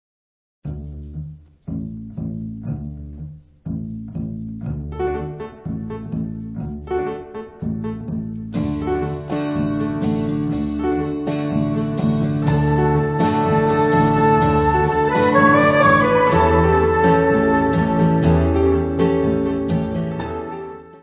Music Category/Genre:  Soundtracks, Anthems, and others